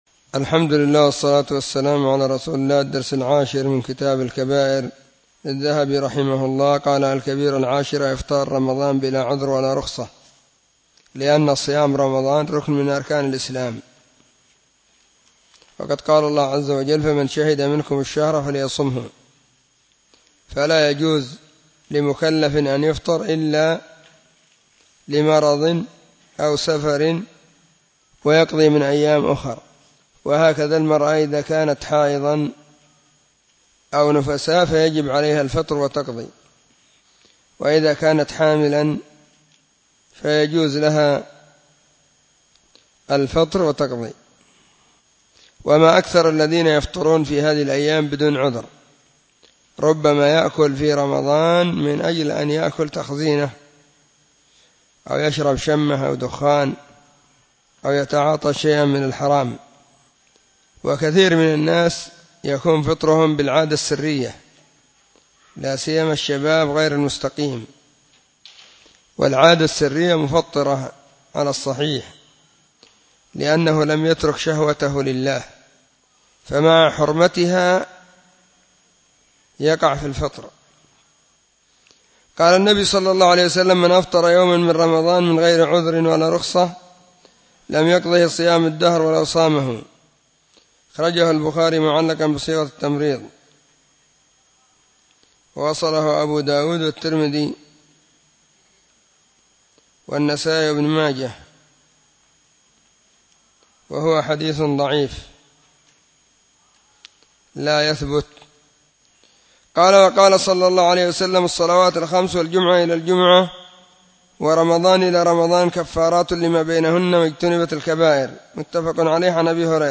🕐 [بين مغرب وعشاء – الدرس الثاني]
📢 مسجد الصحابة – بالغيضة – المهرة، اليمن حرسها الله.